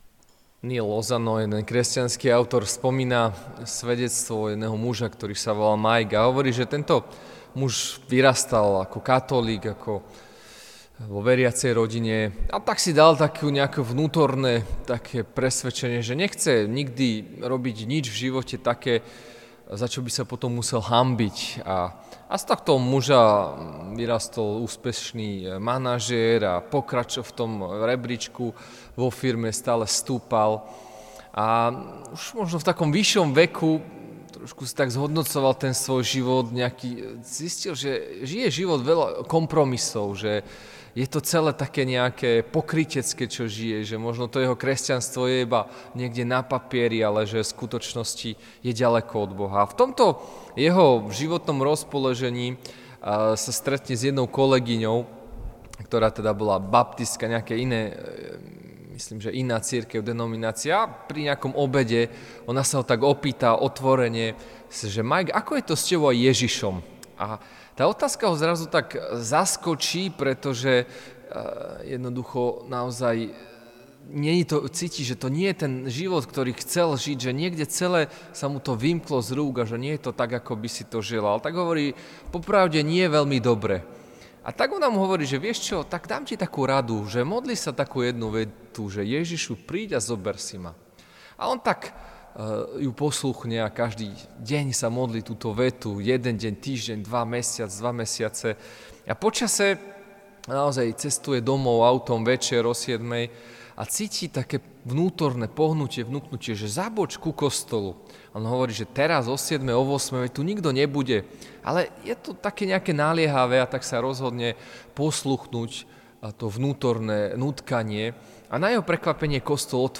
Piatkové kázne